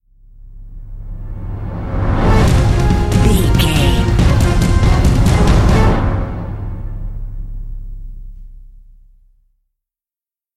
Epic / Action
Fast paced
In-crescendo
Aeolian/Minor
A♭
Fast
strings
drums
orchestral hybrid
dubstep
aggressive
energetic
intense
bass
synth effects
wobbles
driving drum beat